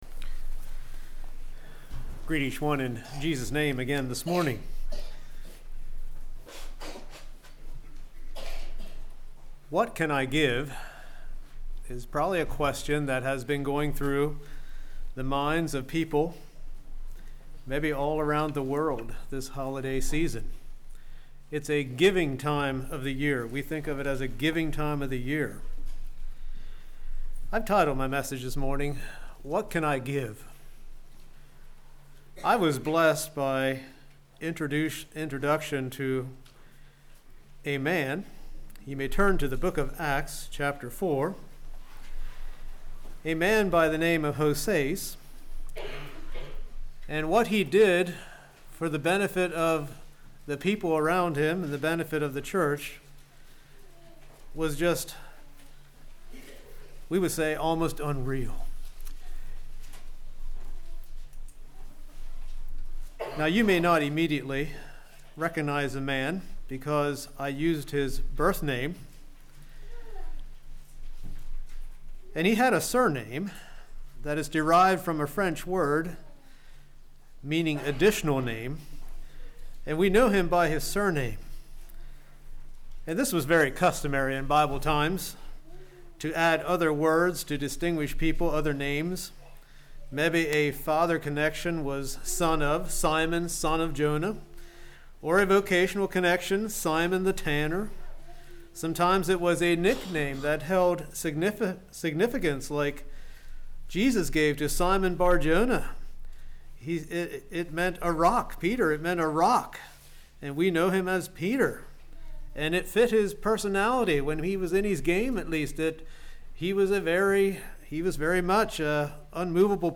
What can you give to make an impact on future generations? This sermon gives some pointers from Barnabas' life on how we can pass on the things we have been blessed with.